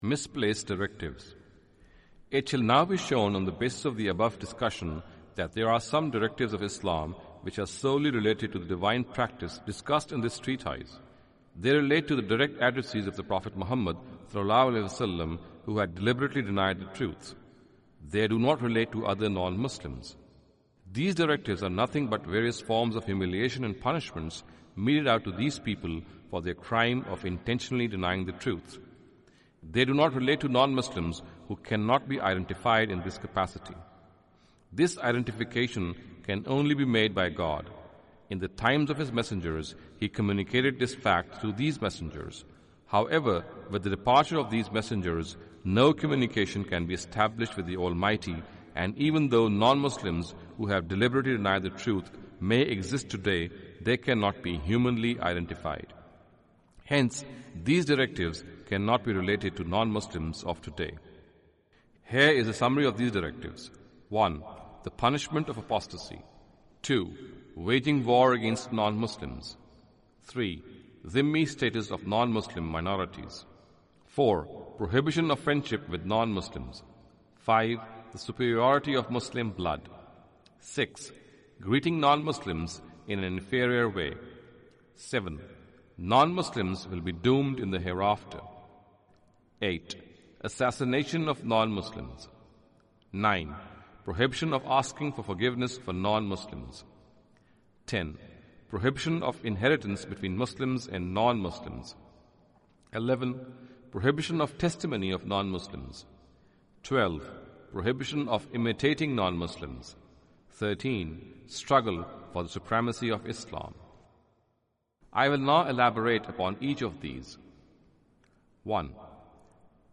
Category: Audio Books / Playing God /
Audio book of English translation of Javed Ahmad Ghamidi's book "Playing God".